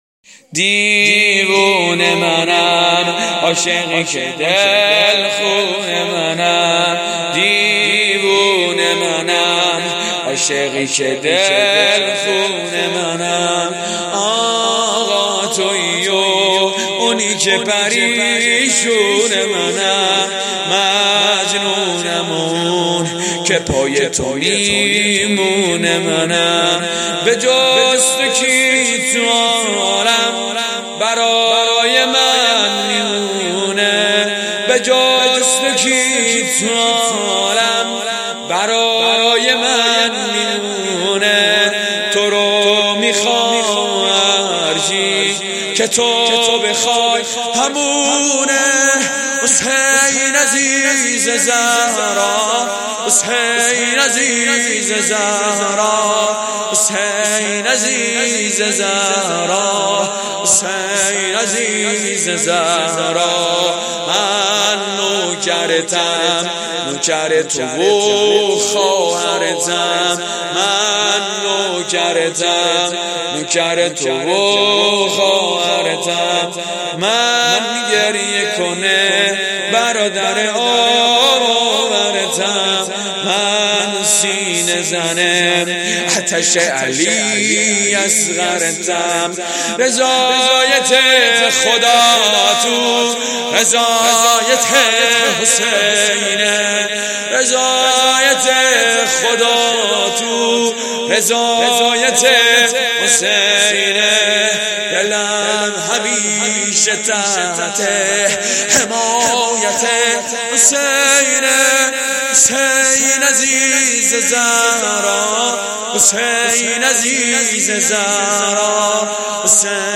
دیوونه منم شور شب دوم فاطمیه دوم